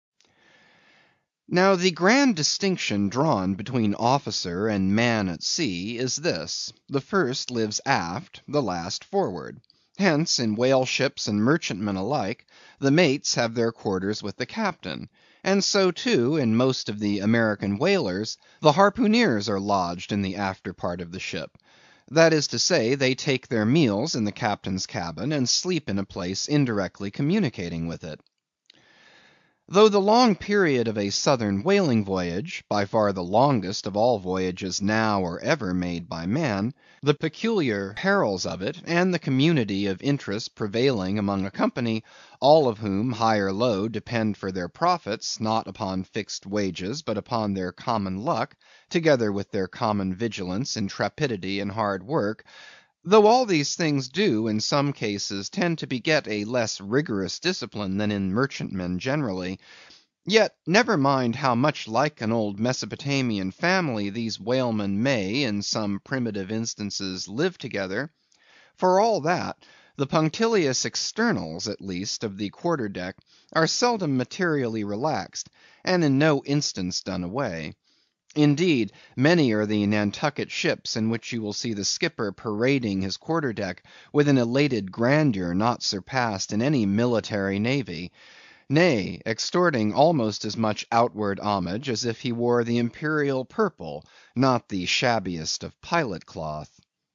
英语听书《白鲸记》第389期 听力文件下载—在线英语听力室